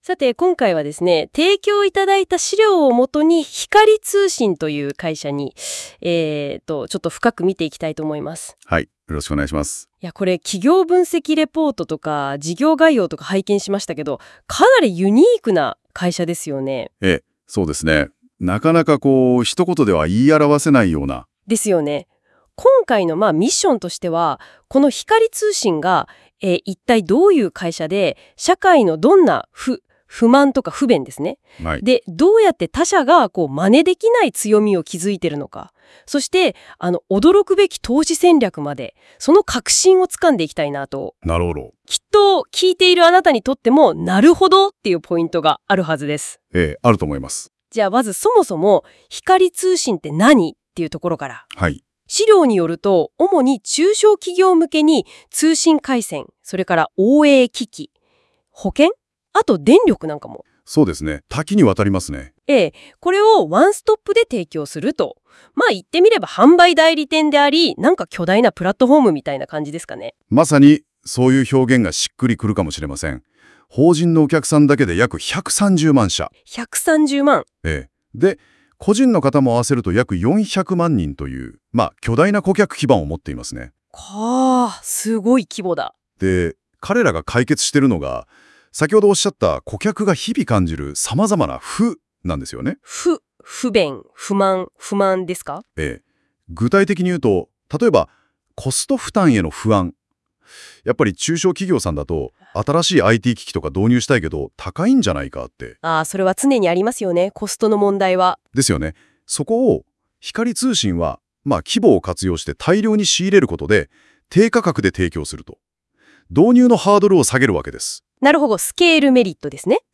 レーザーテックに続いて、光通信（9435）のラジオも撮ってみました。